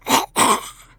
Zombie Voice Pack - Free / Zombie Grunt
zombie_grunt_006.wav